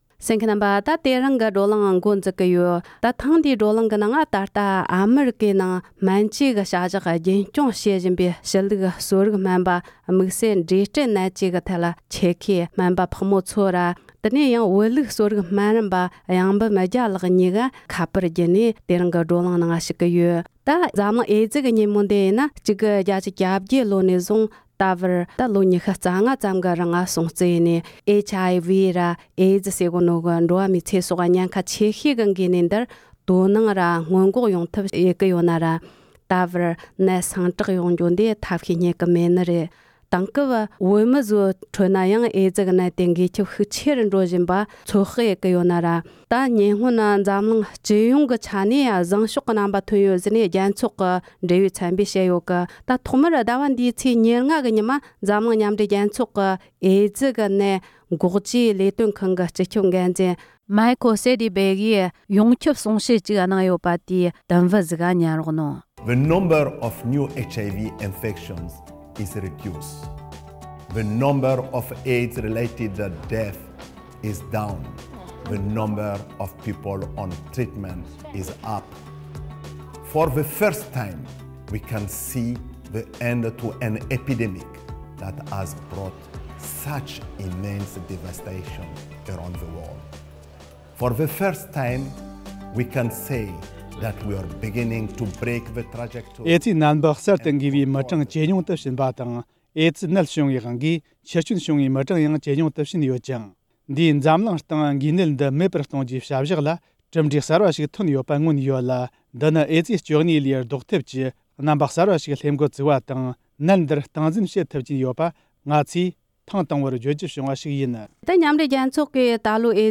སྤྱིར་འཛམ་གླིང་ནང་ཨེཙིའི་ནད་གཞི་འགོ་མཁན་ཉུང་དུ་ཕྱིན་ཡོད་ཀྱང་བོད་ནང་ཁྱབ་བཞིན་ཡོད་པའི་སྐོར་གླེང་མོལ།